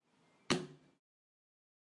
描述：Sonido de golpe al reloj
Tag: 时间 蜱滴答 时钟